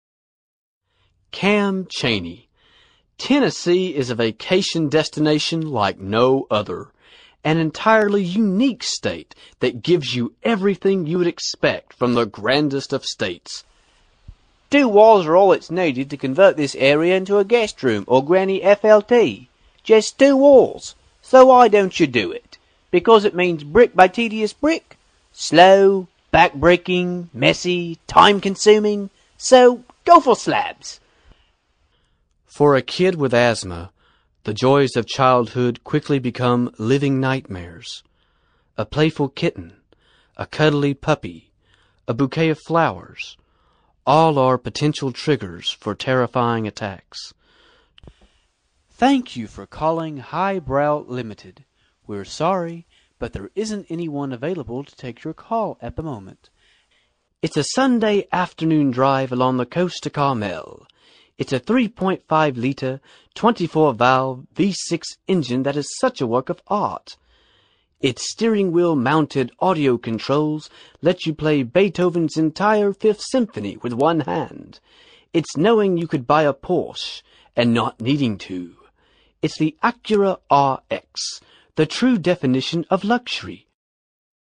narration, amusing, funny
mid-atlantic
Sprechprobe: Werbung (Muttersprache):